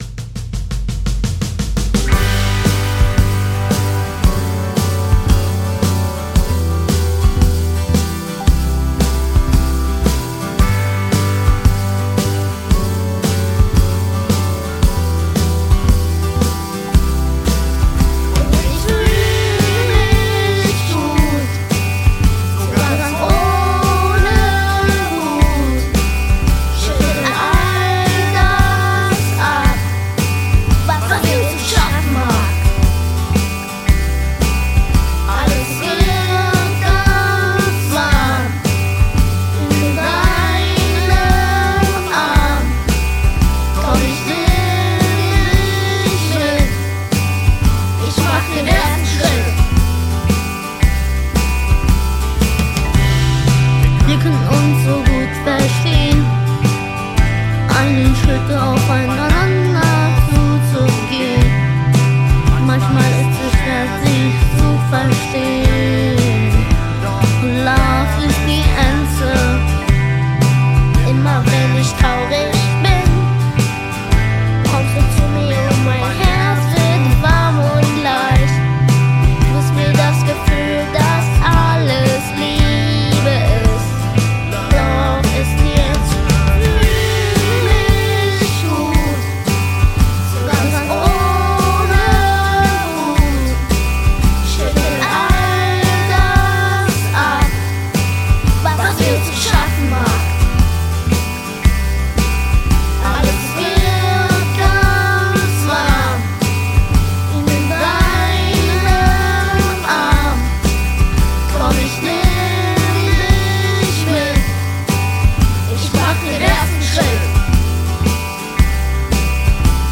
Gesang
Keys
Synth
Bass
E-Gitarre
Congas
Schlagzeug